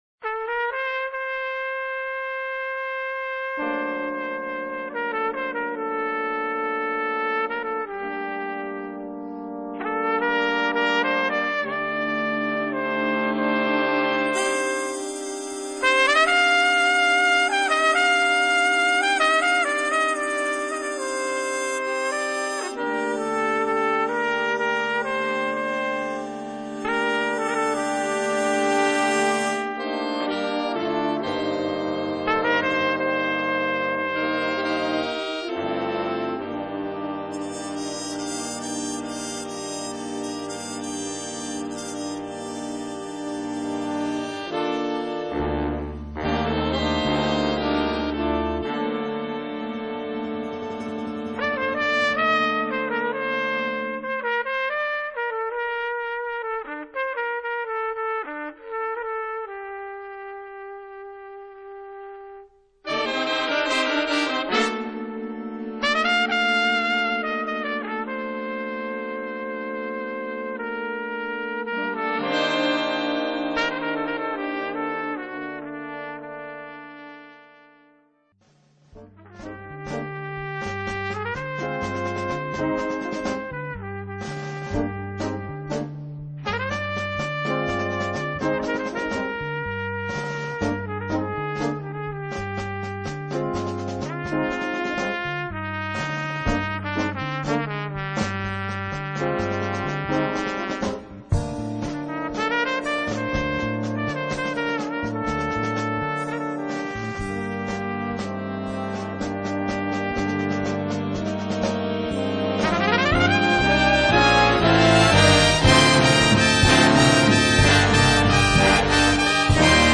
Big band jazz